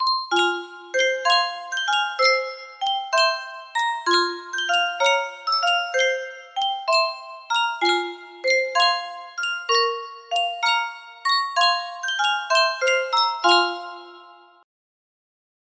Sankyo Spieluhr - Ich hab die Nacht geträumet TYE music box melody
Full range 60